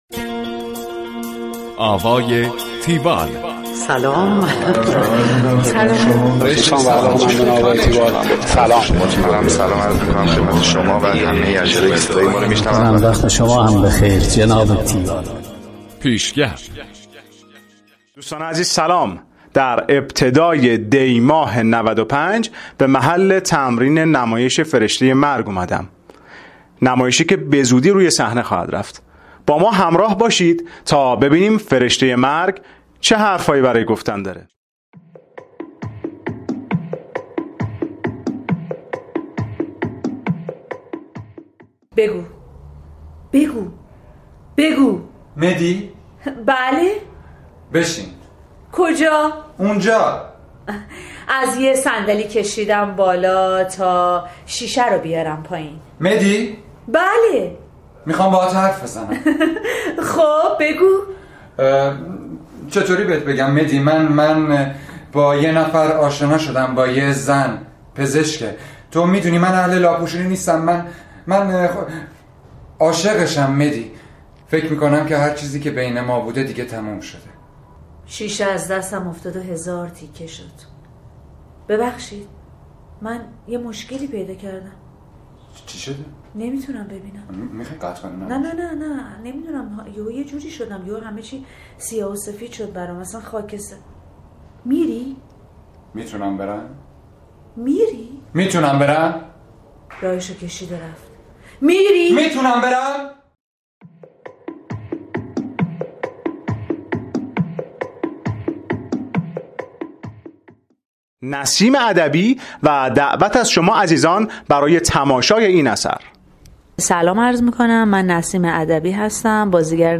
گزارش آوای تیوال از نمایش فرشته مرگ
گفتگو با